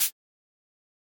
Shaker.wav